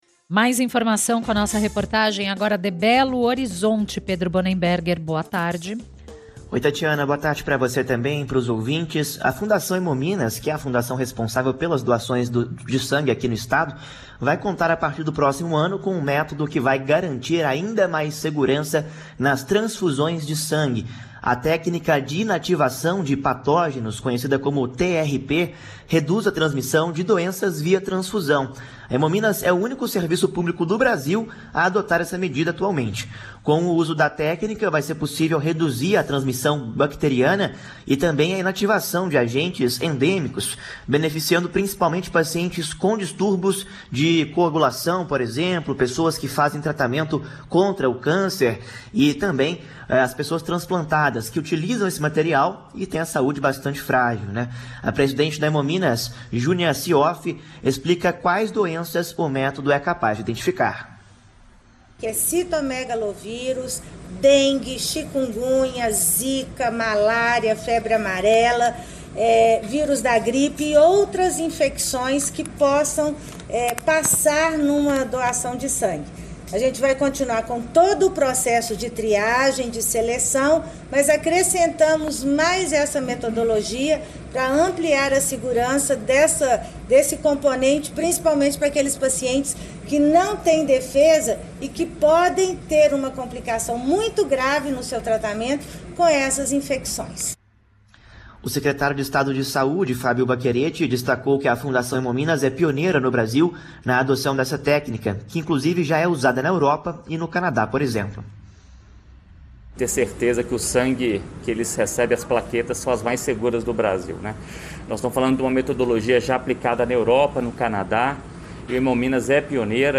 Reportagens publicadas na mídia (impressos, vídeos e áudios) de interesse da Fundação Hemominas.